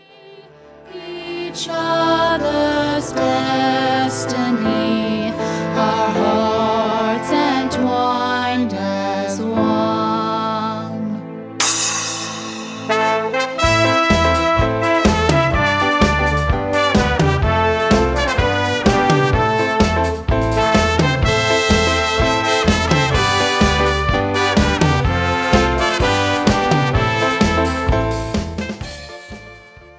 A Musical Download Album for $11.99
cast recording